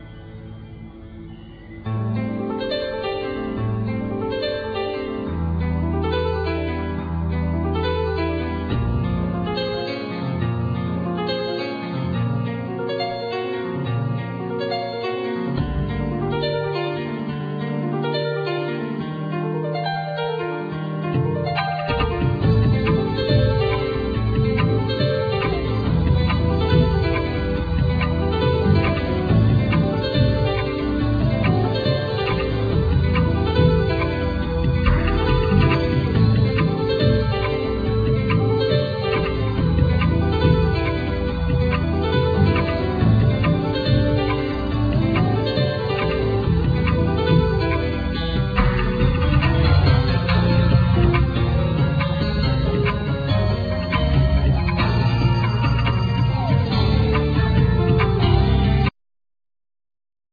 Piano,Keyboards,Programming
Guitar
Drums,Percussions
Flute